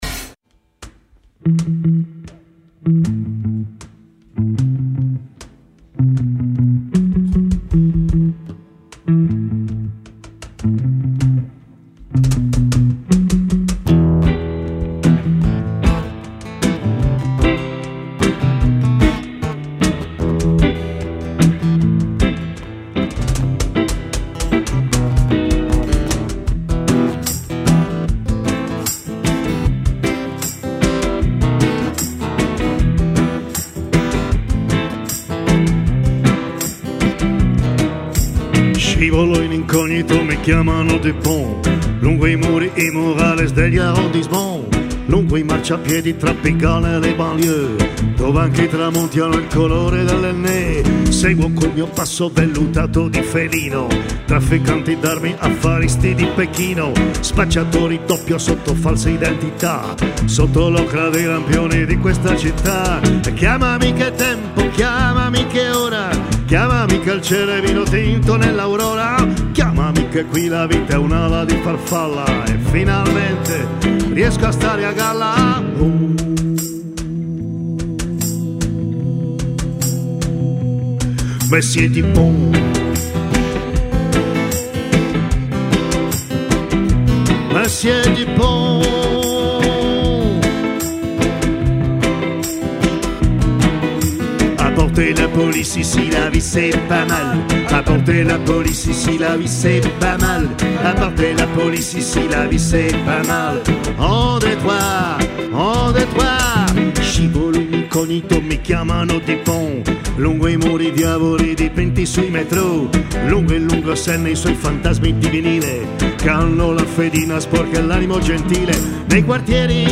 Ogni giovedì alle 21.30, l’auditorium Demetrio Stratos di Radio Popolare ospita concerti, presentazioni di libri, reading e serate speciali aperte al pubblico.